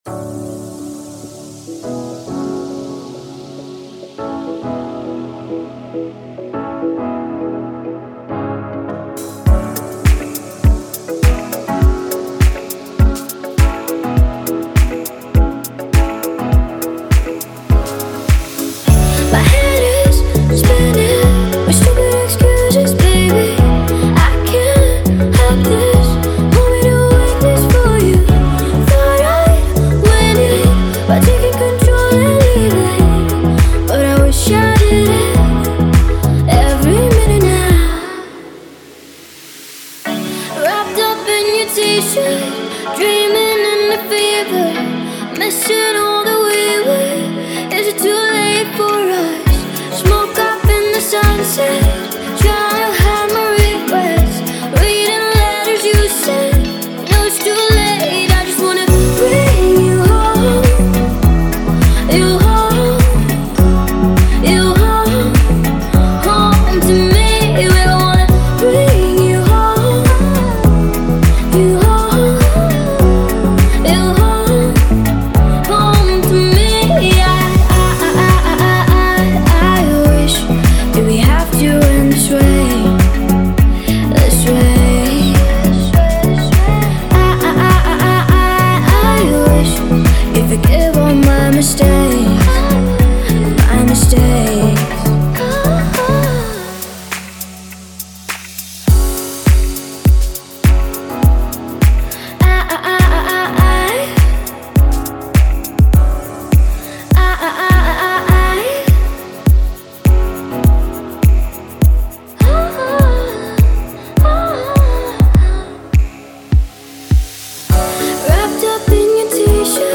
это завораживающая композиция в жанре прогрессивного хауса